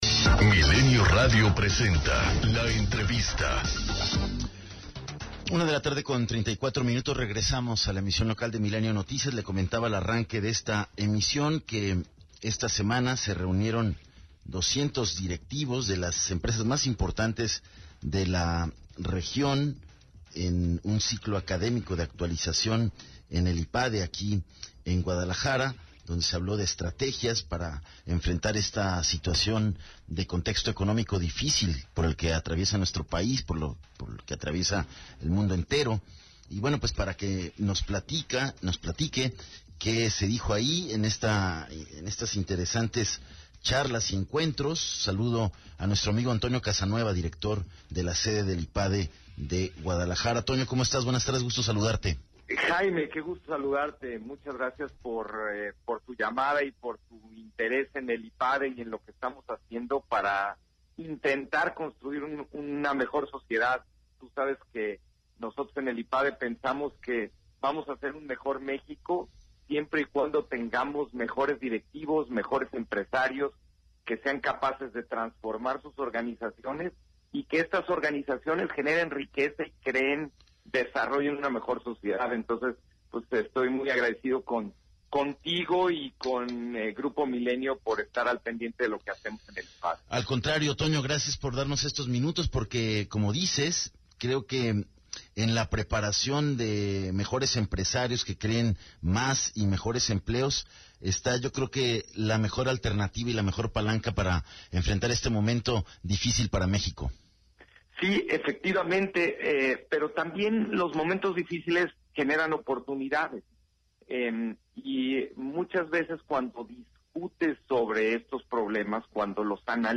ENTREVISTA 040915